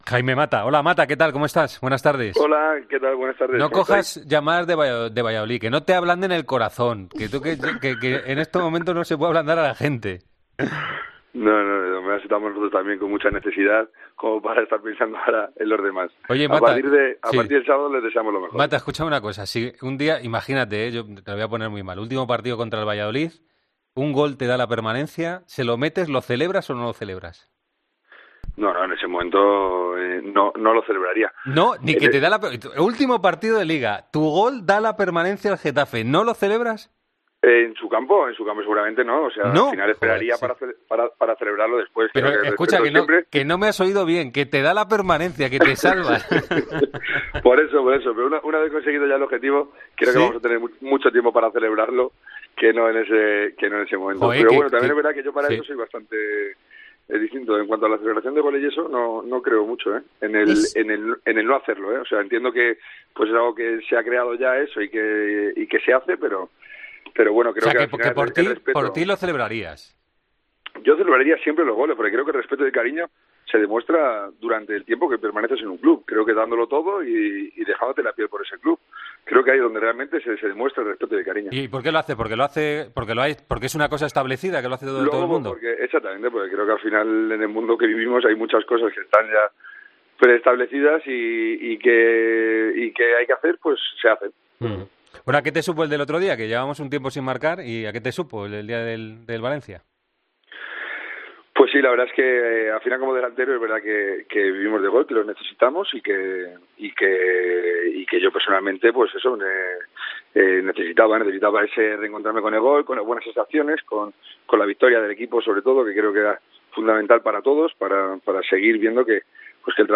Entrevista en Deportes COPE